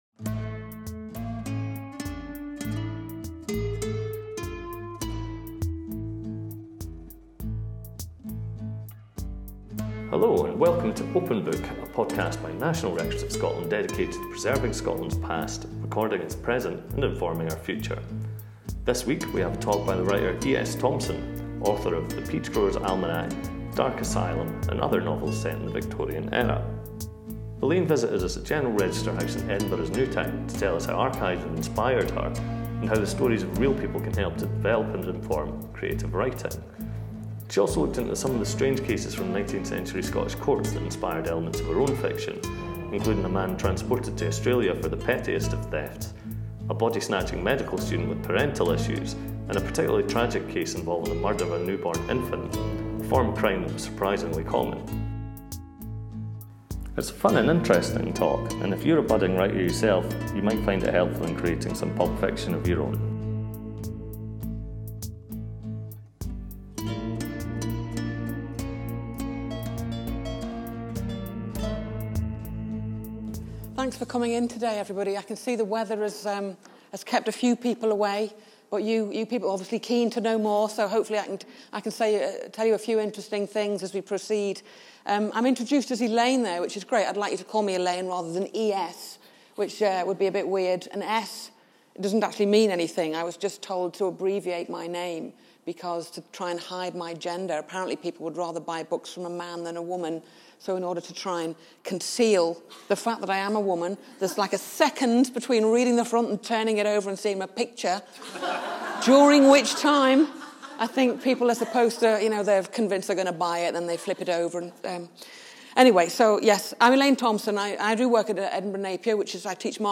Open Book, the National Records of Scotland Podcast, is now available to download via iTunes . Recorded on 20 November 2017 at General Register House, Edinburgh.